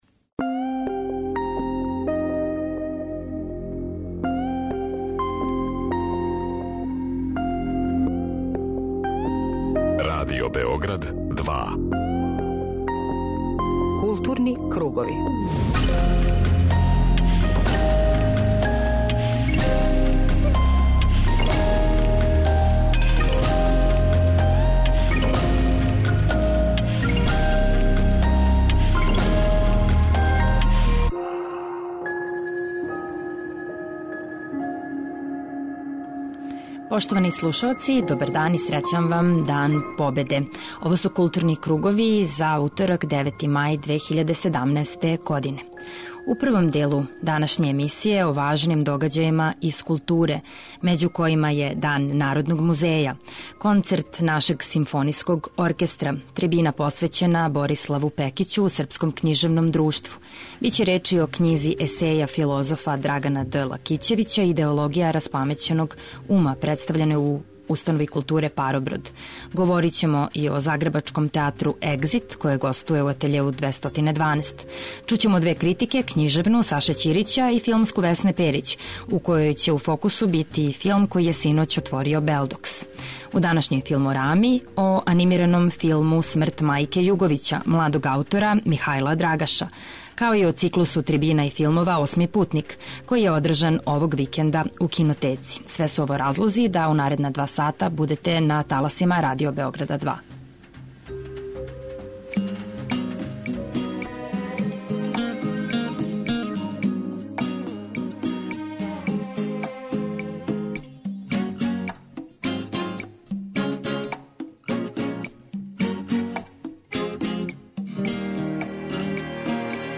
Чућете шта су о историјату овог серијала и његовом развоју на трибини говориле филмаџије и љубитељи.
преузми : 41.21 MB Културни кругови Autor: Група аутора Централна културно-уметничка емисија Радио Београда 2.